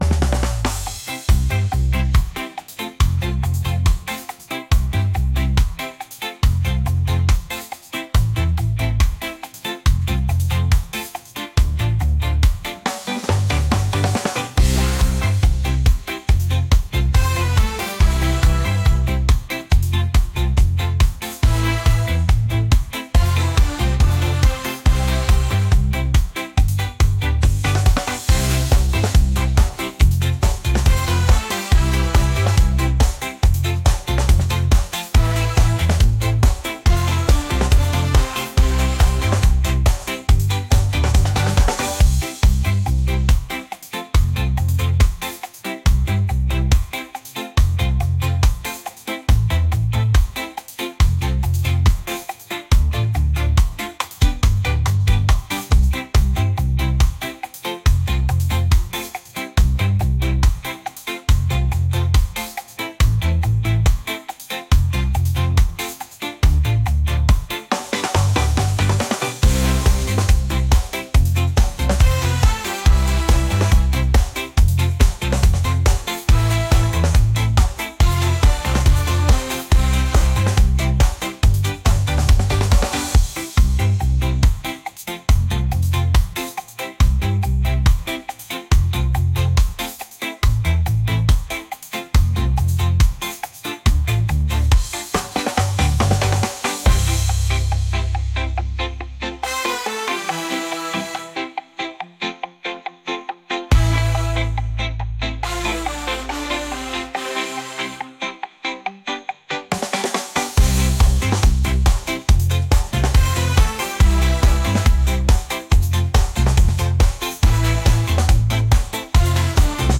reggae